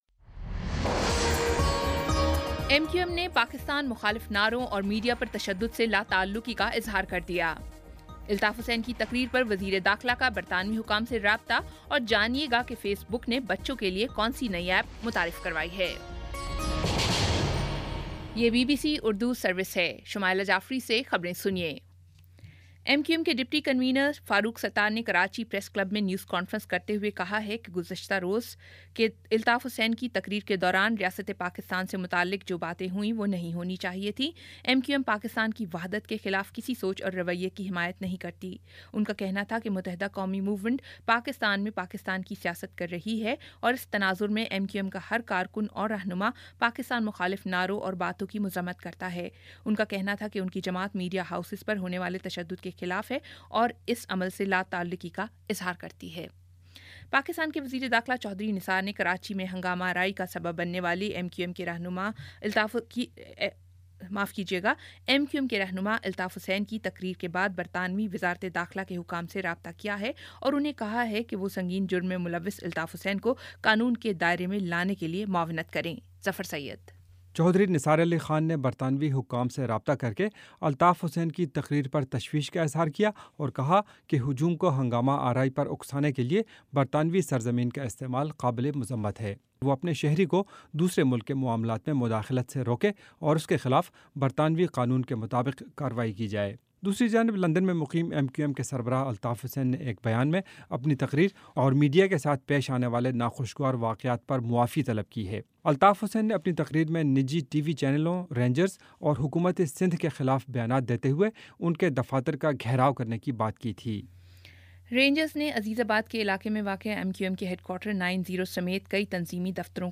اگست 23 : شام پانچ بجے کا نیوز بُلیٹن